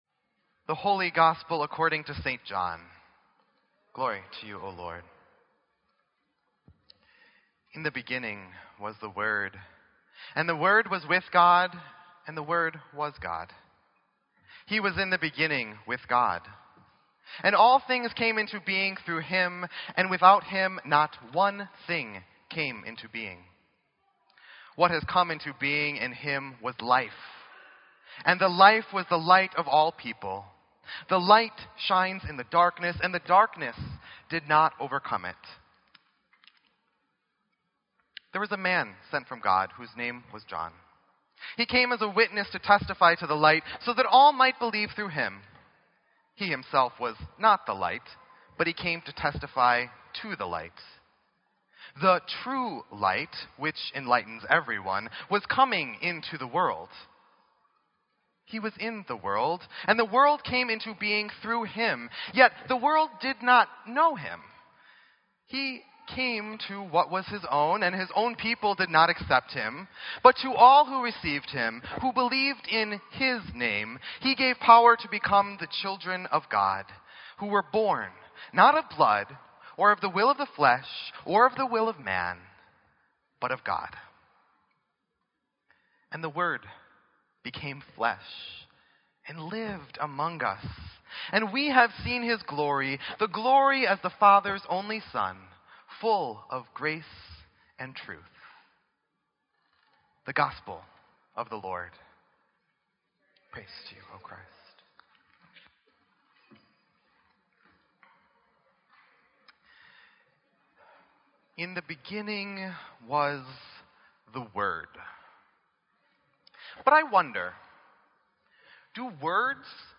Sermon_12_25_16.mp3